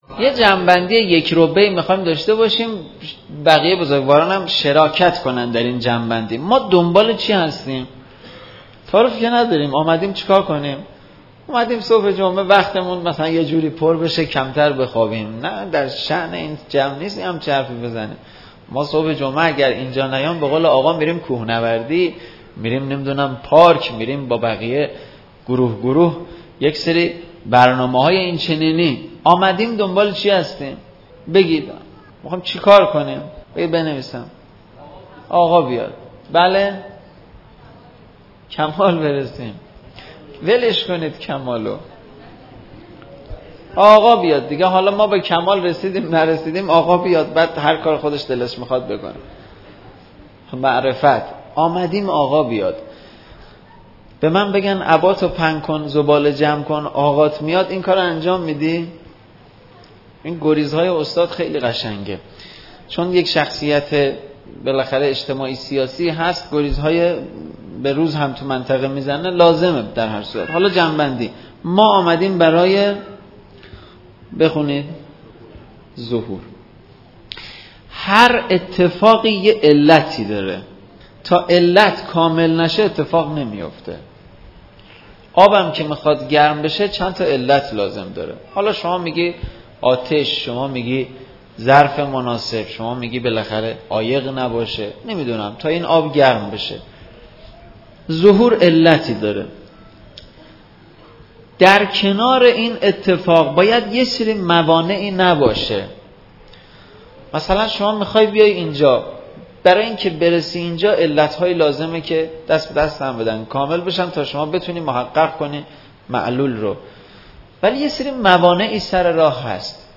صوت سخنرانی قسمت دوم